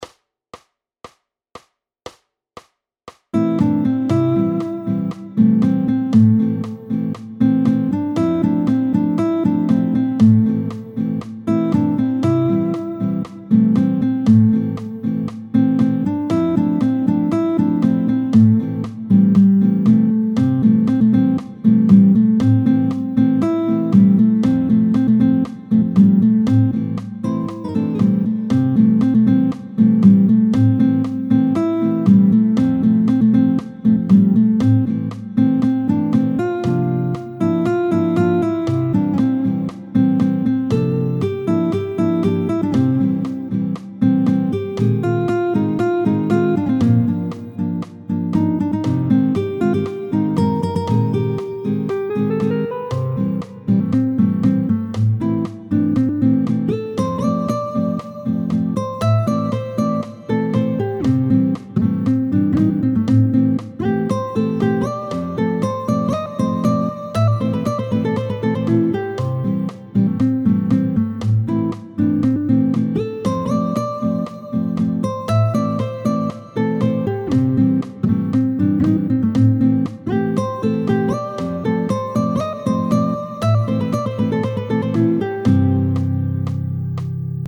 tempo 118